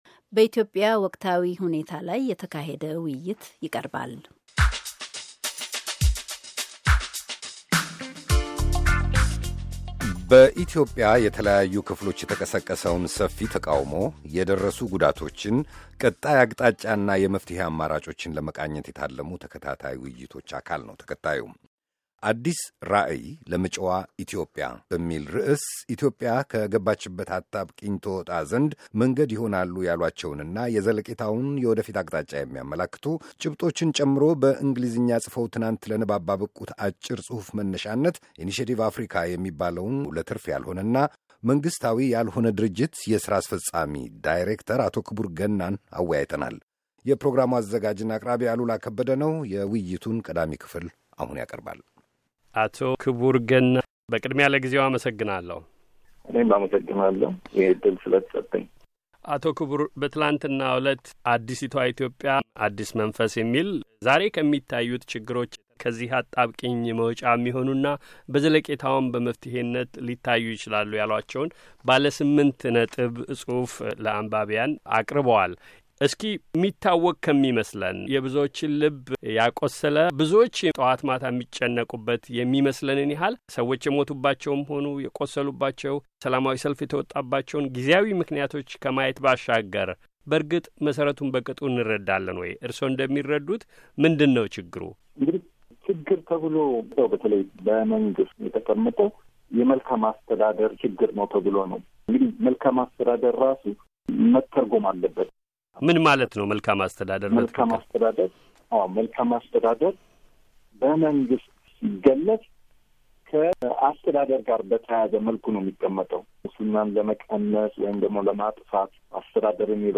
የውይይቱን ሁለተኛ ክፍል ከዚህ ያድምጡ:- ከአጣብቂኙ መውጫ ቀጥተኛ መንገዶች